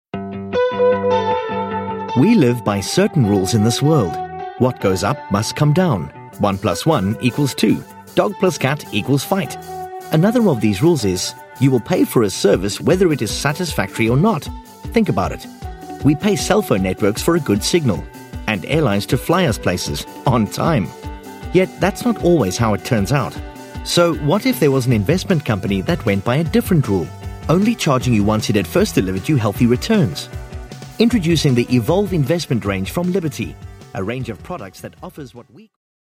britisch
Sprechprobe: eLearning (Muttersprache):
Super Diverse - Can put on and hold almost any accent and add character to any product.